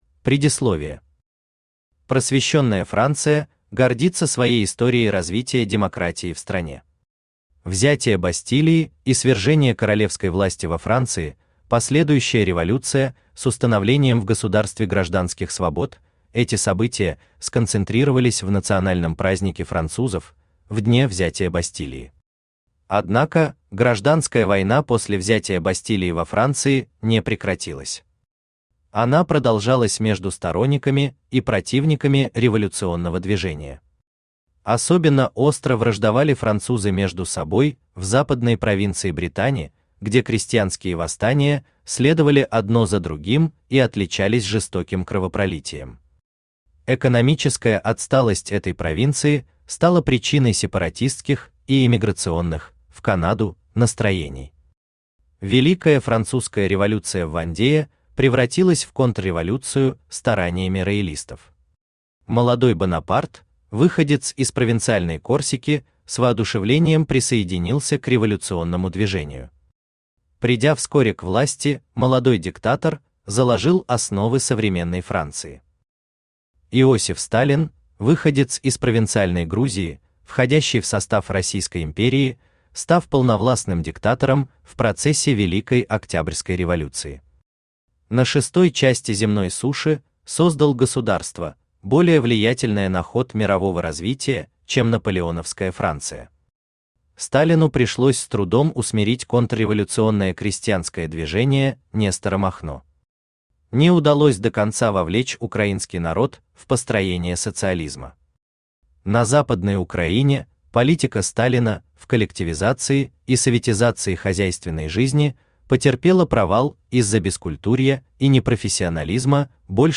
Аудиокнига МГБ | Библиотека аудиокниг
Aудиокнига МГБ Автор Вадим Гринёв Читает аудиокнигу Авточтец ЛитРес.